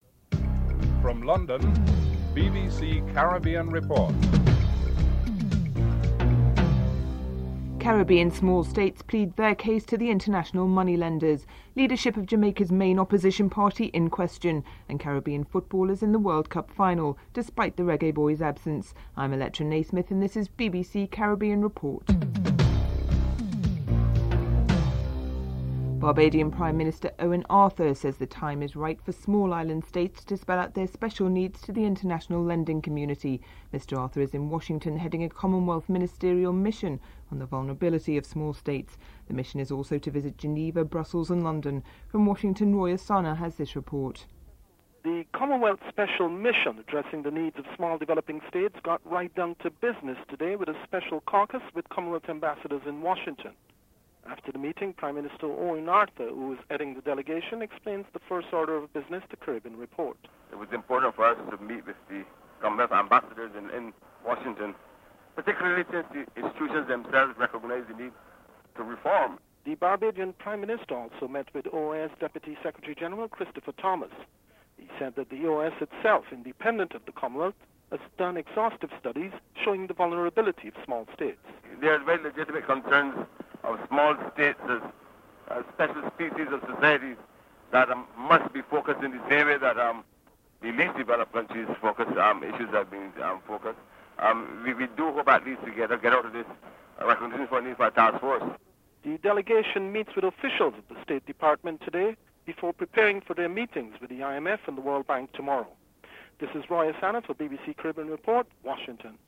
Foreign Minister Ralph Maraj is interviewed (11:44-23:51)